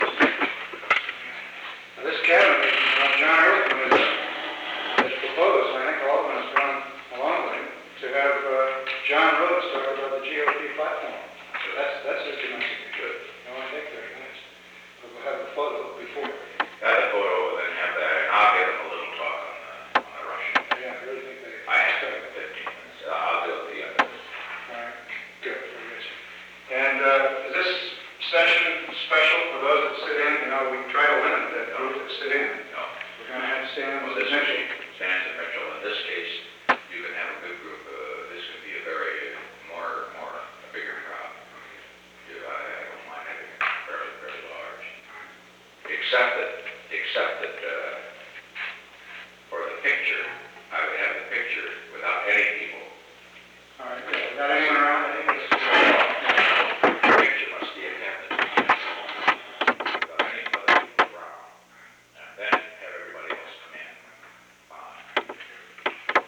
Secret White House Tapes
Conversation No. 735-5
Location: Oval Office
The President met with Alexander P. Butterfield.